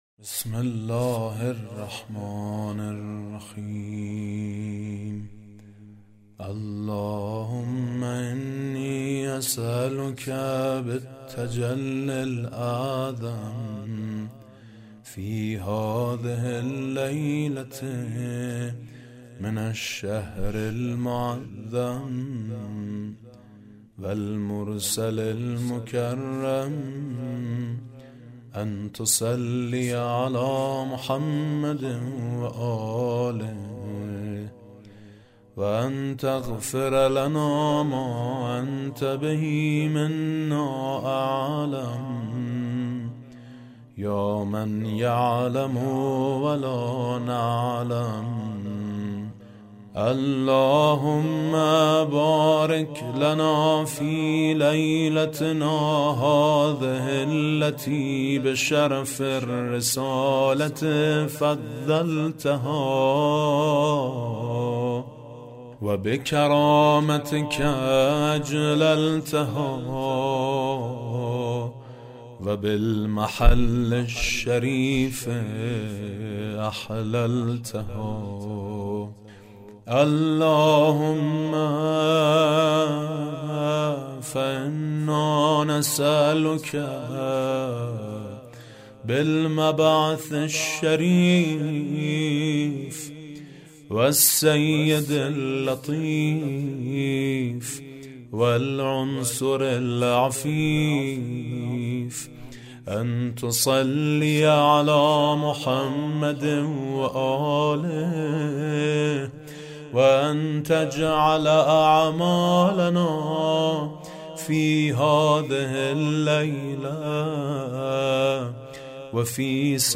صوت| دعای شب مبعث با نوای میثم مطیعی